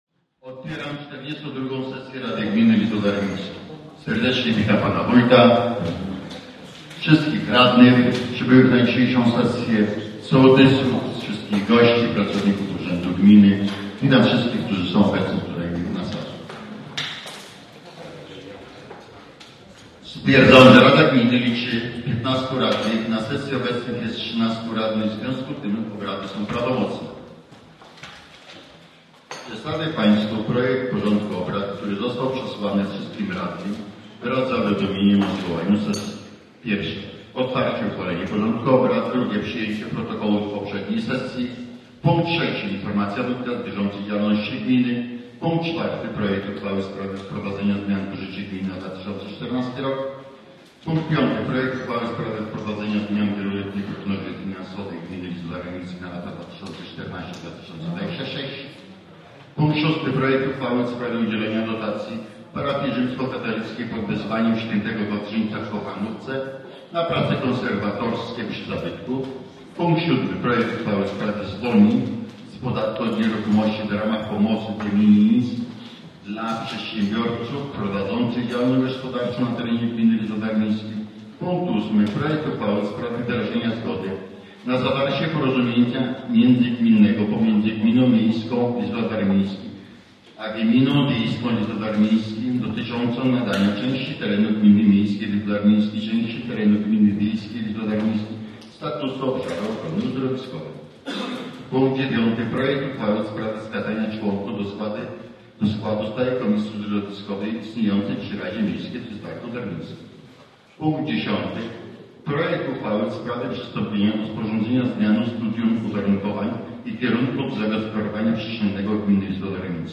W wolnych wnioskach mieszkańcy gminy kierowali do Wójta bardzo interesujące pytania.
42-Sesja-Rady-Gminy-Całość.mp3